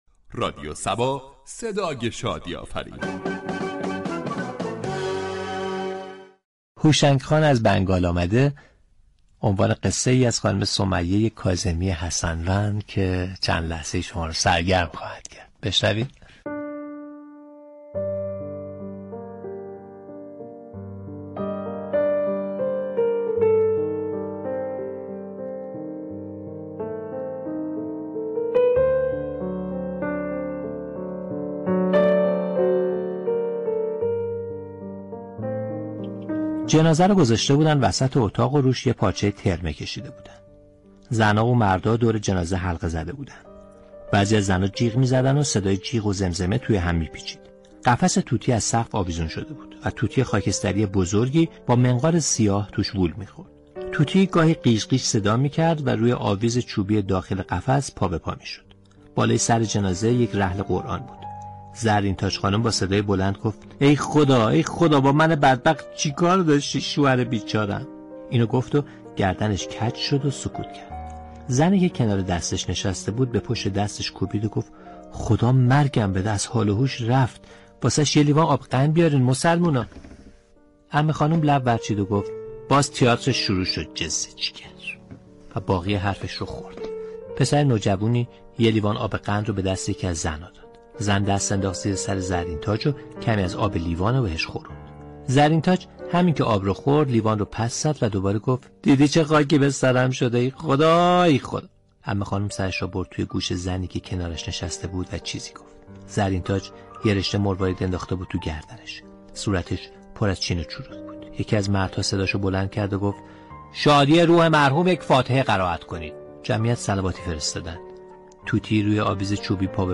در ادامه شنونده بخشی از قصه "هوشنگ خان از بنگال آمده " به قلم سمیه كاظمی با صدای منصور ضابطیان از برنامه "بر بوی زلف یار " باشید.